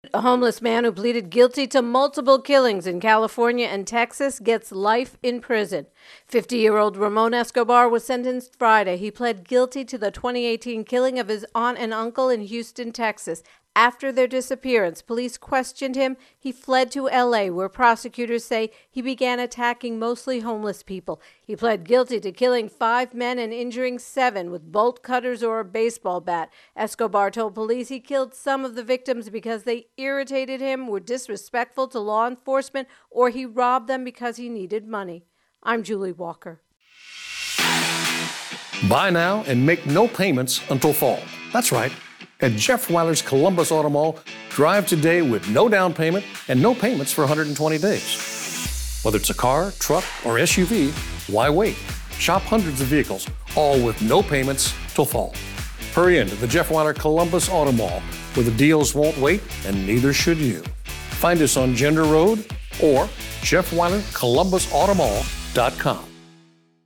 Homeless Beatings intro and voicer